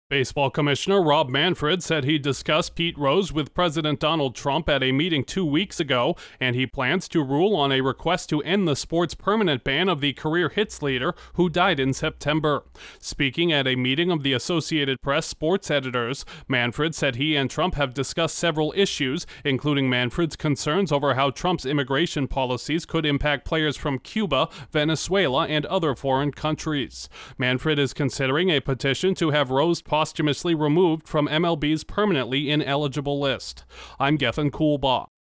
A reinstatement for Pete Rose could be in the works after a meeting between baseball’s commissioner and the president. Correspondent